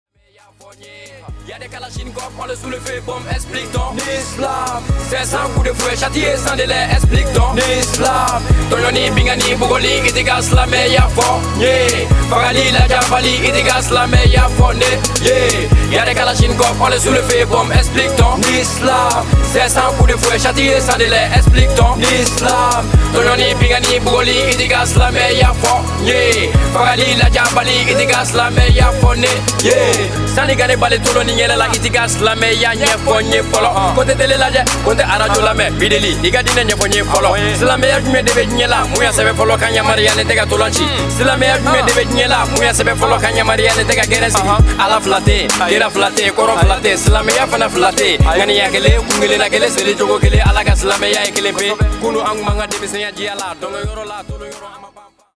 hip-hop tracks
Malian rapper